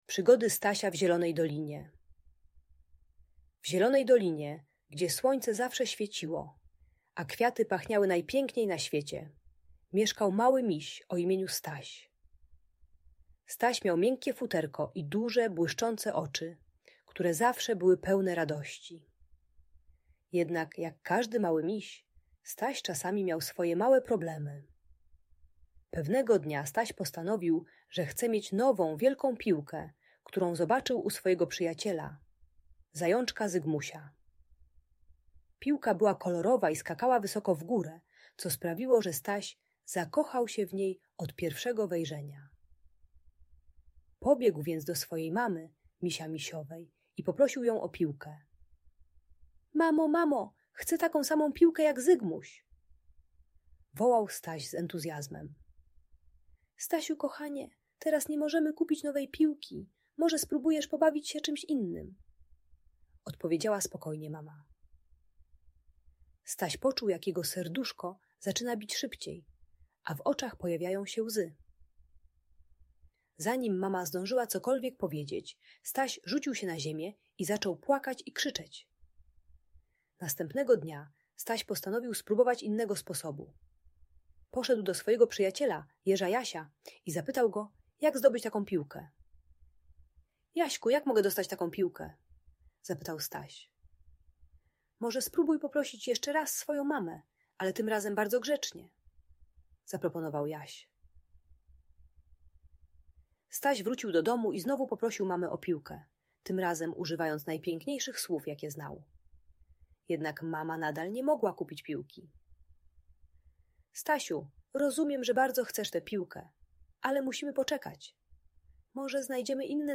Przygody Stasia w Zielonej Dolinie - Magiczna story - Audiobajka